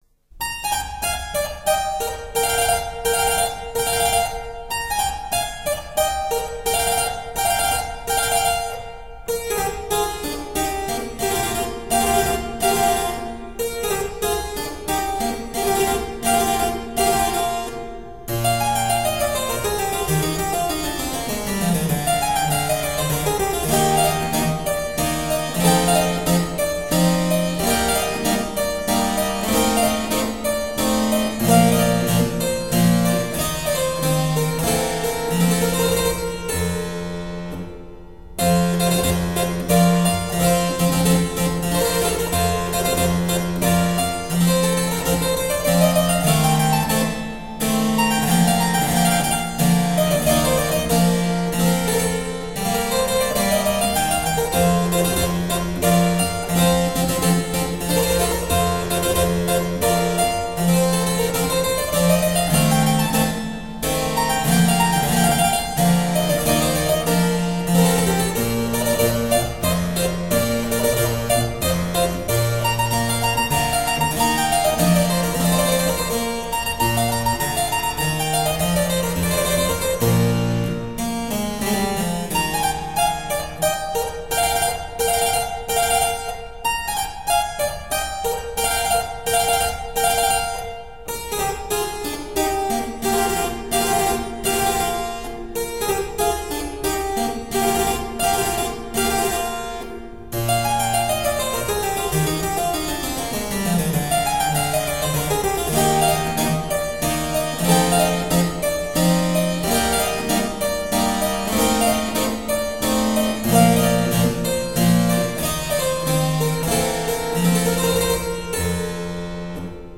Sonate pour clavecin Kk 380 : Presto